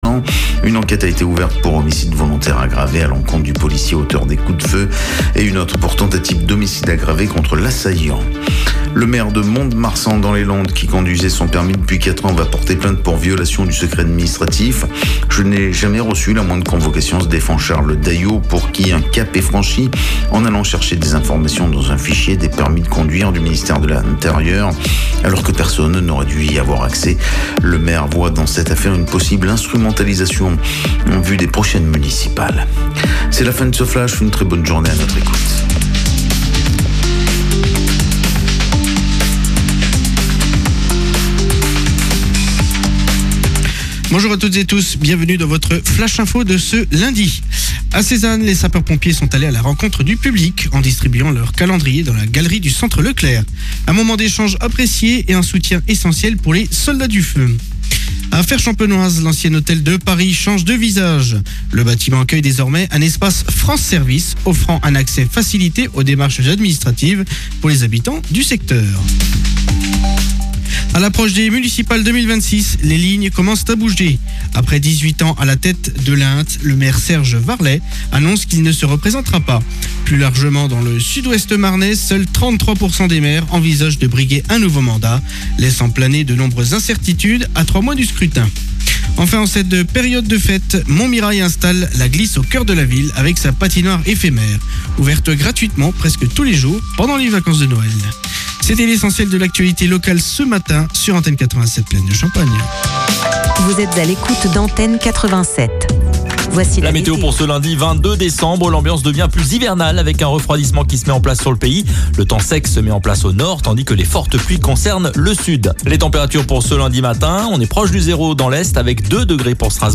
Info-trafic, actualités locales, manifestations, idées de sorties… Le Carnet des Plaines prend le pouls du territoire et met en lumière ce qui se passe près de chez vous. Chaque jour, l’émission propose aussi des chroniques de découvertes locales, des initiatives qui méritent le détour, et parfois la rencontre avec un invité :un artisan passionné, un acteur de la vie associative, un élu, une jeune voix engagée ou une figure bien connue des Plaines.